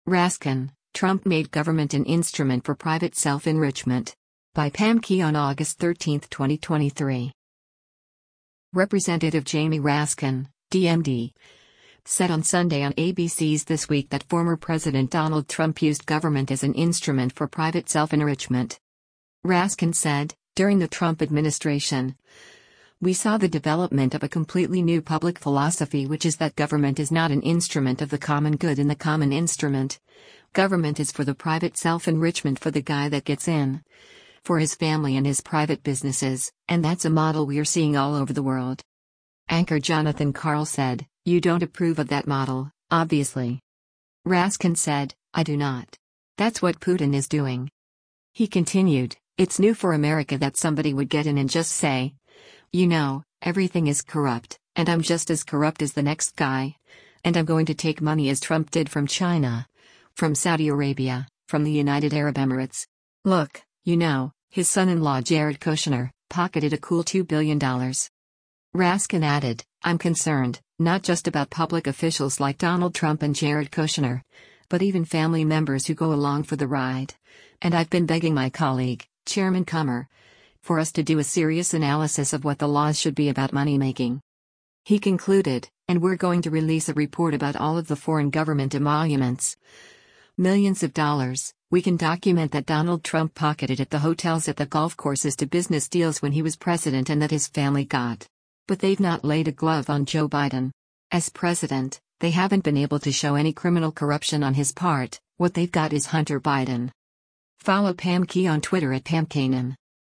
Representative Jamie Raskin (D-MD) said on Sunday on ABC’s “This Week” that former President Donald Trump used government as an “instrument for private self-enrichment.”
Anchor Jonathan Karl said, “You don’t approve of that model, obviously.”